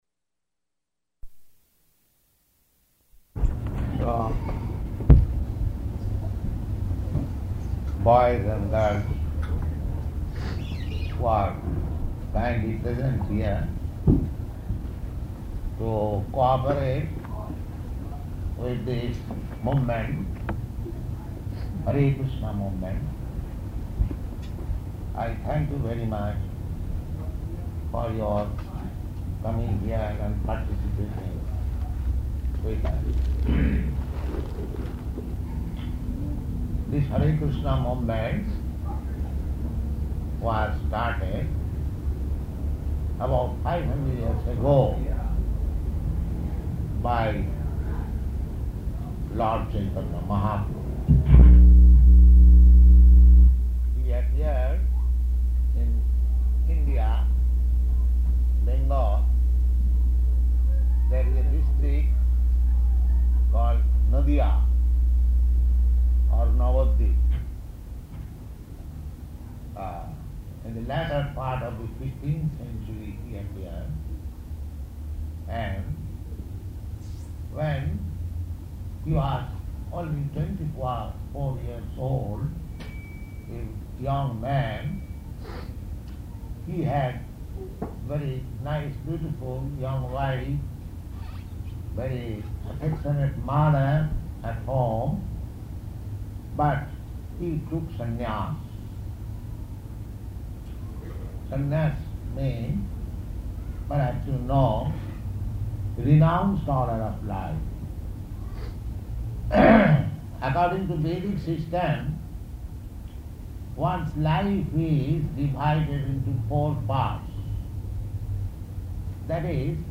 Lecture
Type: Lectures and Addresses
Location: Honolulu